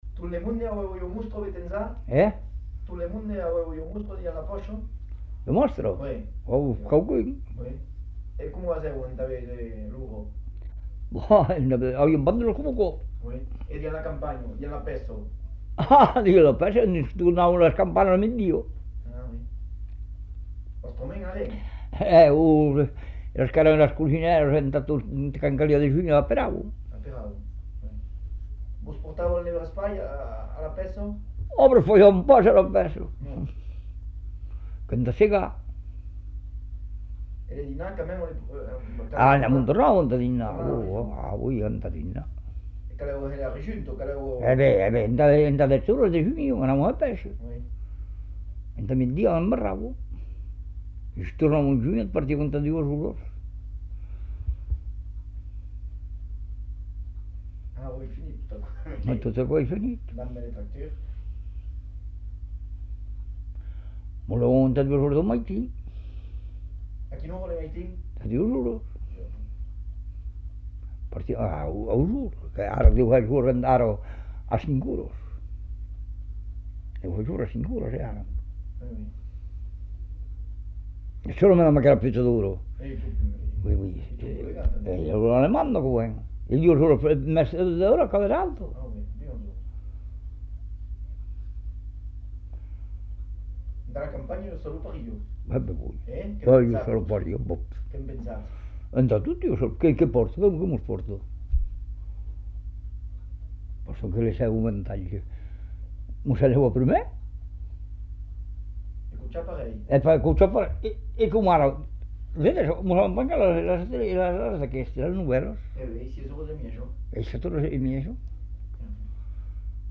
Aire culturelle : Savès
Genre : témoignage thématique